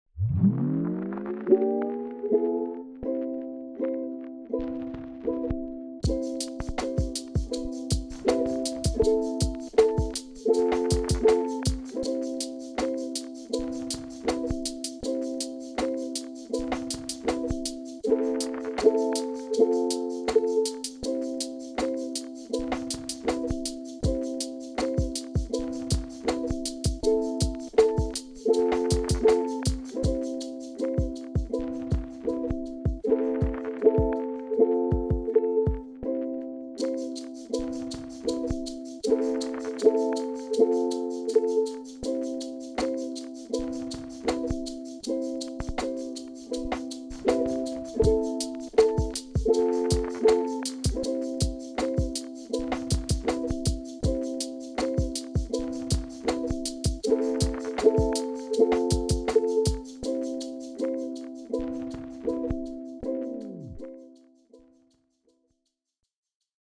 The first time I worked with a sample. It made a lot of fun finding good percussions drums etc.